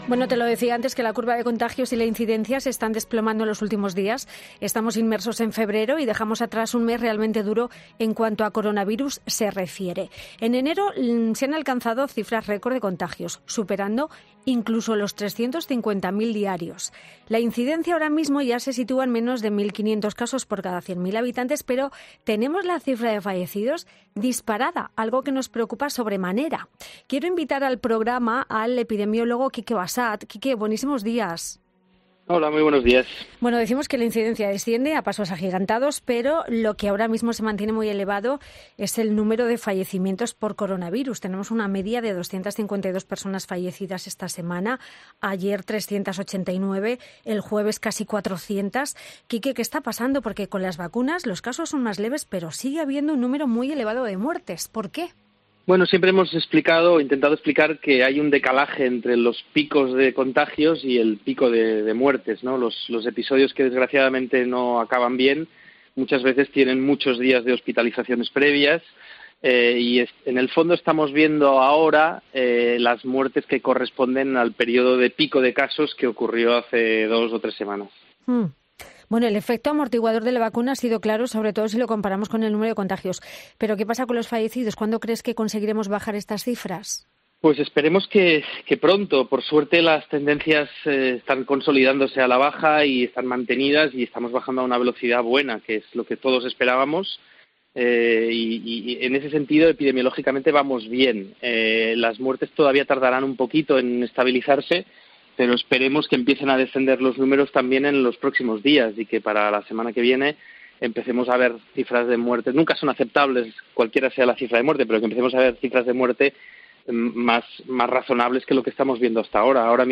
Quique Bassat, epidemiólogo, explica este sábado en Fin de Semana de COPE el motivo de esta diferencia entre los datos de muertes y el de contagios tras el paso de la variante Ómicron.